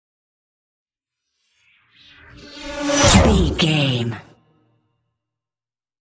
Pass by fast speed engine
Sound Effects
Fast
pass by
car
vehicle